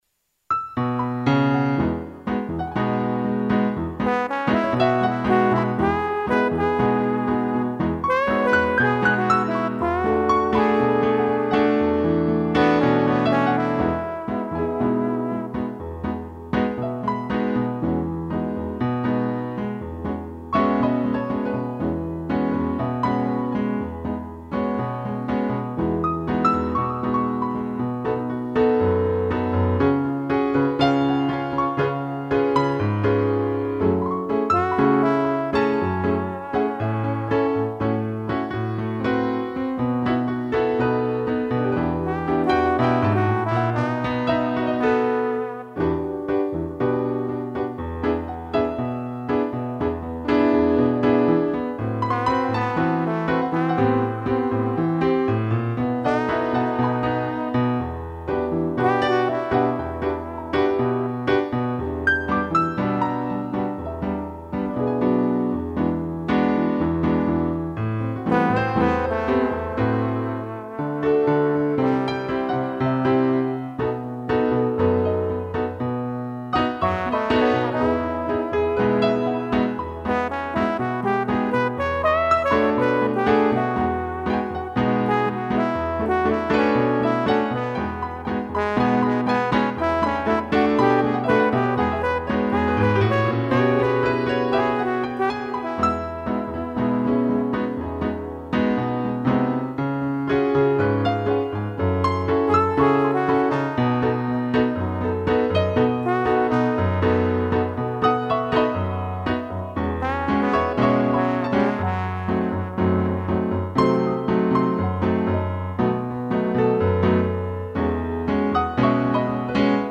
2 pianos e trombone
(instrumental)